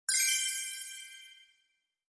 ui_interface_112.wav